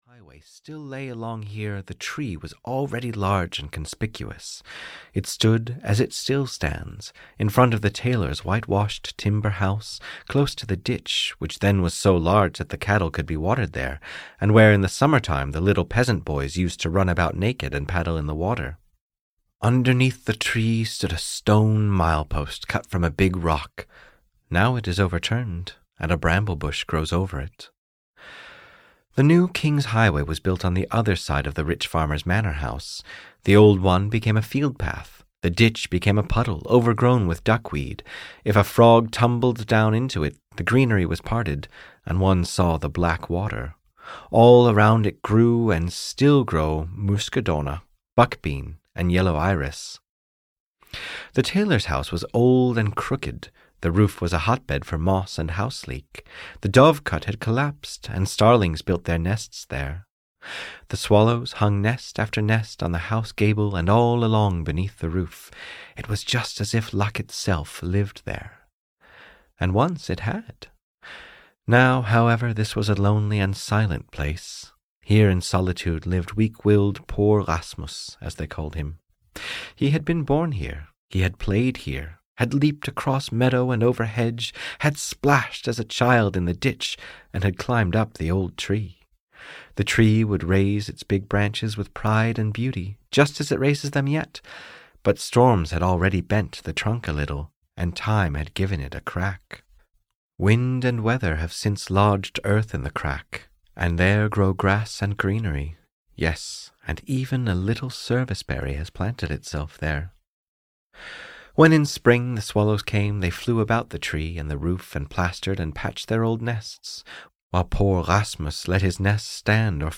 What Old Johanne Told (EN) audiokniha
Ukázka z knihy